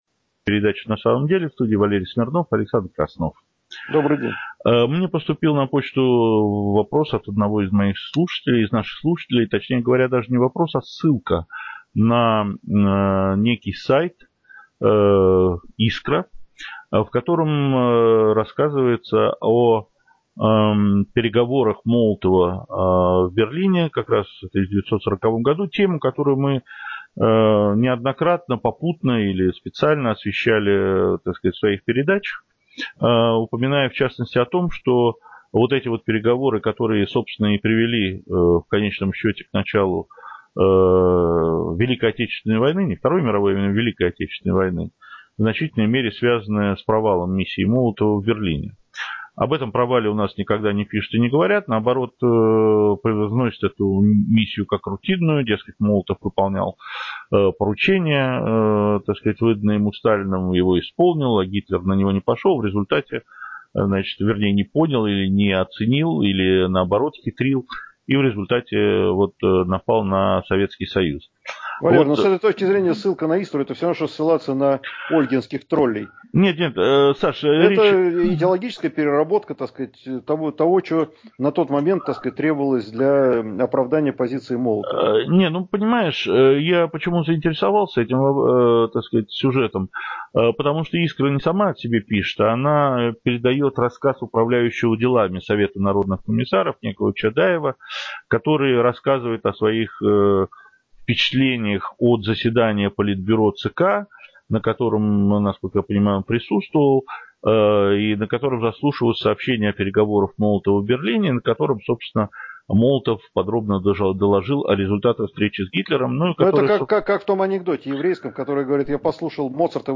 Информационно-аналитическая передача. Темы обзоров - подоплека важнейших политических и экономических событий в России и за рубежом.